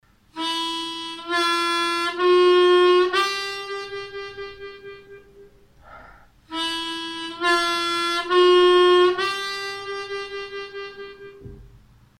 Con este ejercicio aprendemos a controlar los varios grados de bending en el agujero 2 aspirado.
Tonalidad de la armónica: C
AHCOD - Audio_Hole 2 draw bending routine phrase 2.mp3